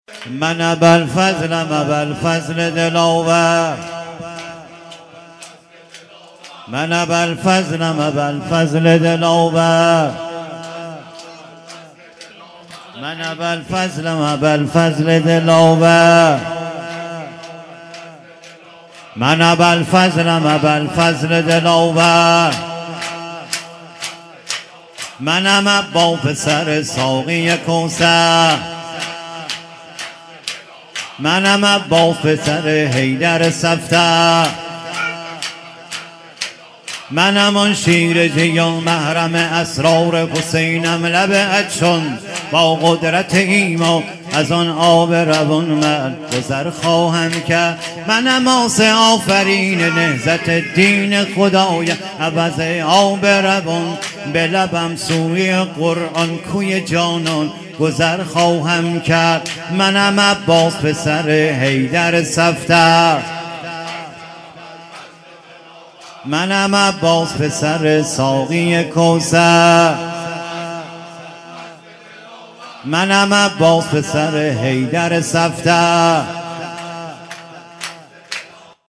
من ابالفضلم ابالفضل دلاور (شور)
محرم و صفر 1395